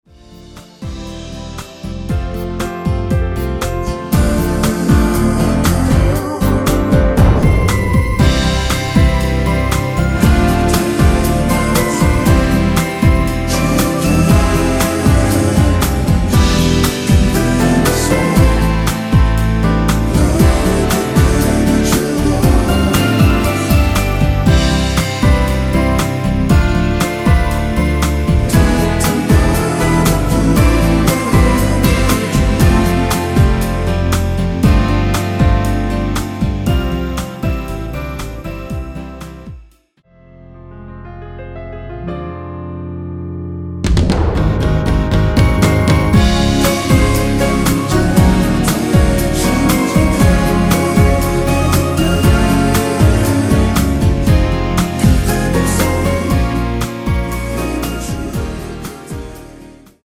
원키에서(-3)내린 코러스 포함된 MR 입니다.(미리듣기 참조)
앞부분30초, 뒷부분30초씩 편집해서 올려 드리고 있습니다.
중간에 음이 끈어지고 다시 나오는 이유는